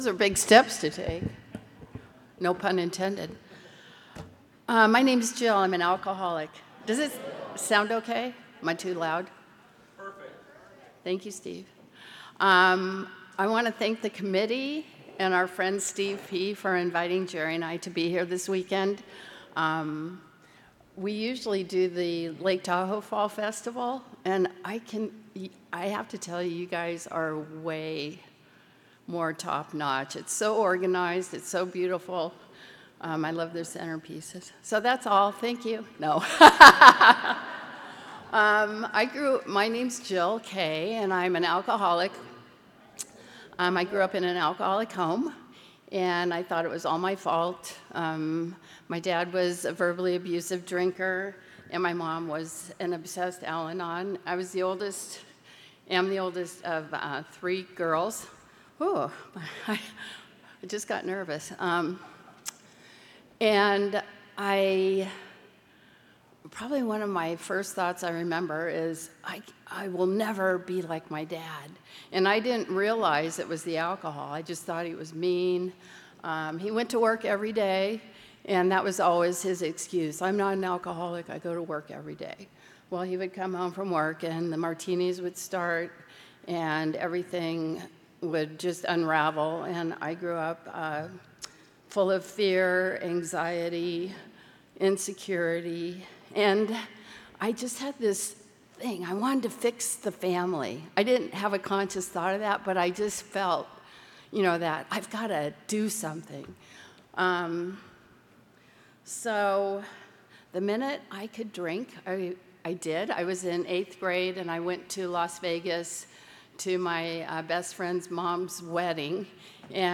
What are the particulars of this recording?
35th Indian Wells Valley Roundup